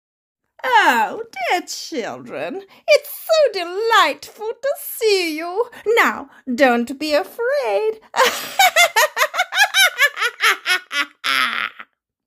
Witch and cackle
45 - 66 ans - Contralto Mezzo-soprano